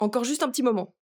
VO_ALL_Interjection_05.ogg